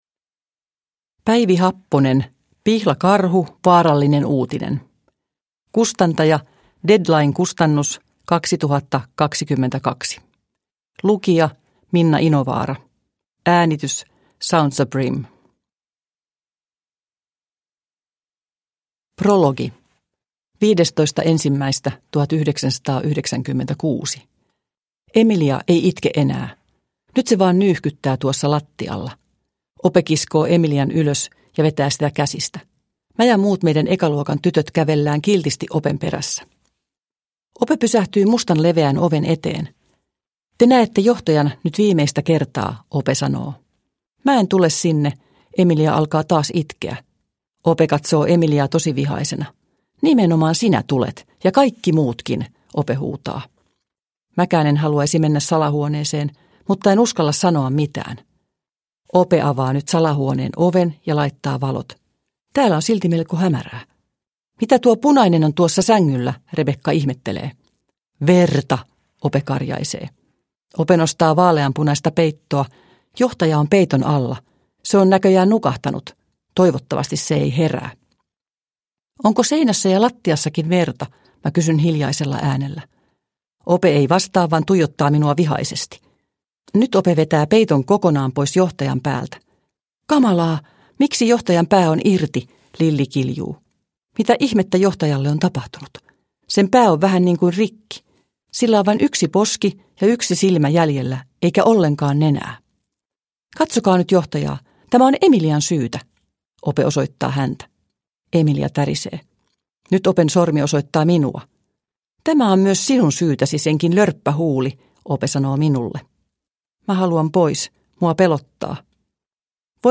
Vaarallinen uutinen – Ljudbok – Laddas ner